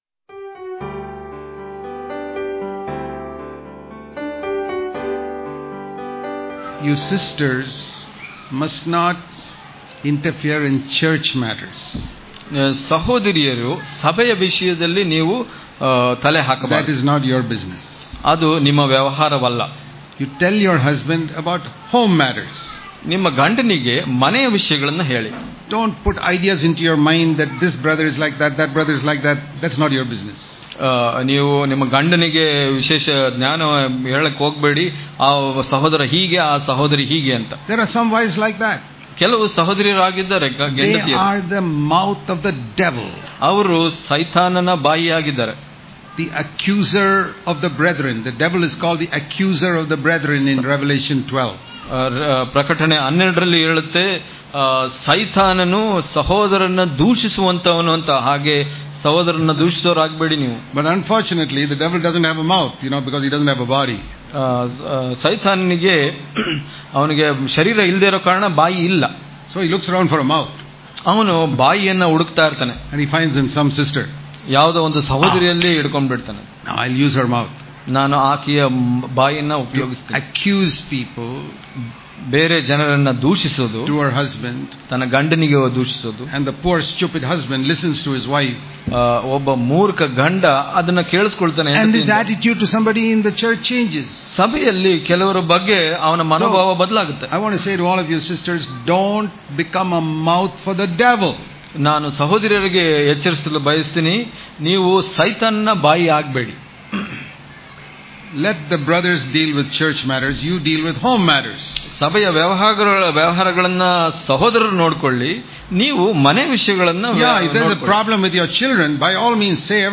ಇಂದಿನ ಧ್ಯಾನ
Daily Devotions